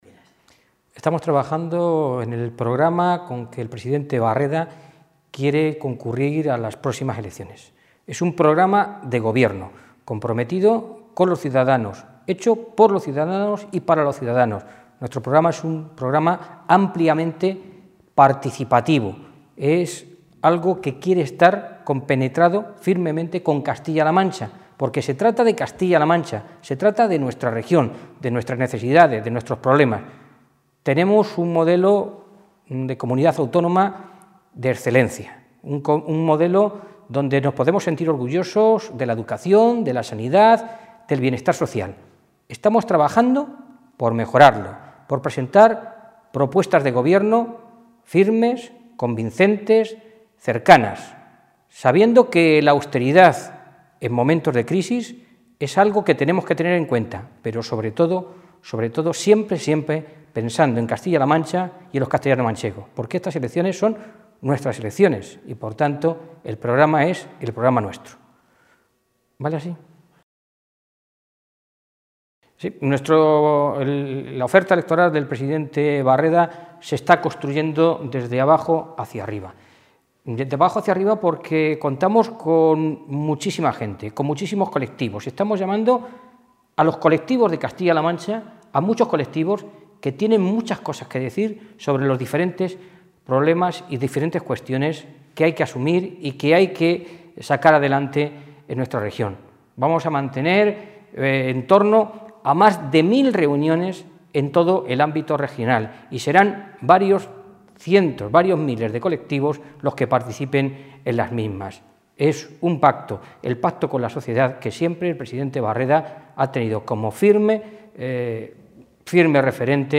Cortes de audio de la rueda de prensa
FERNANDO_MORA.mp3